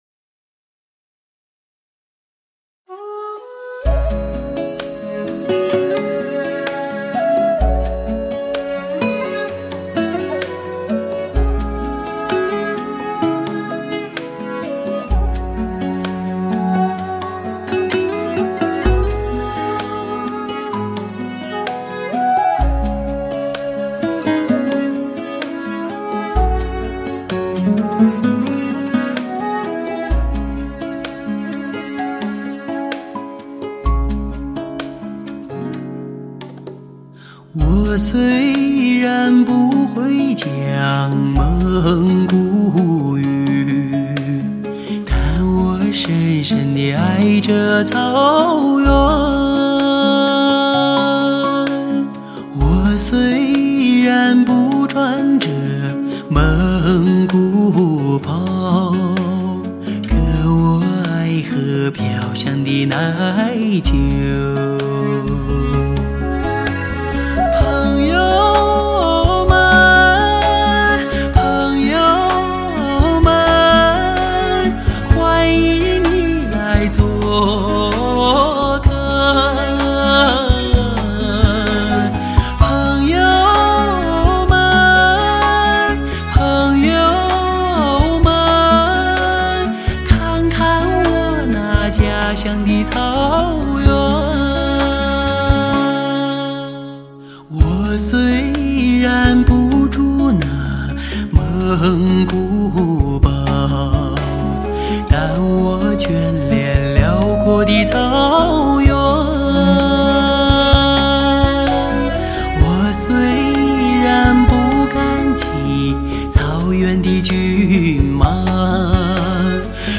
HIFI音乐示范监听必备天碟。